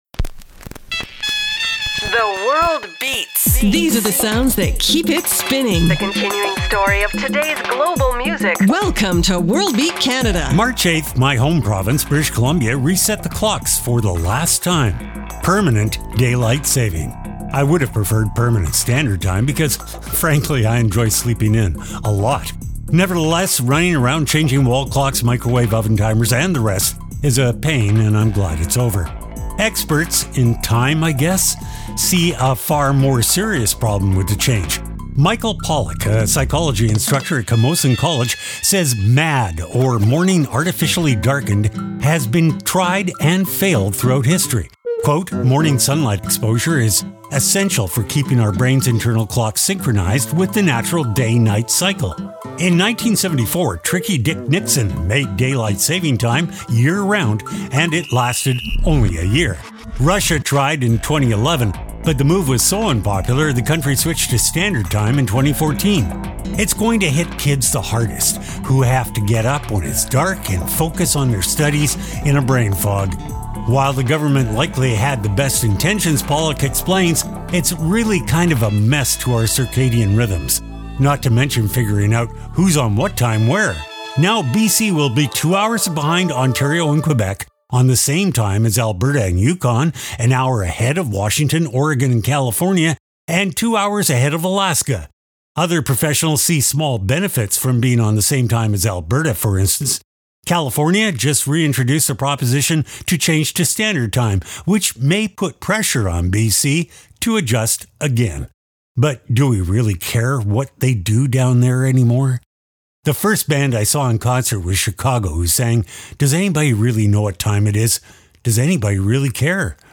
exciting global music alternative to jukebox radio
Weekly Program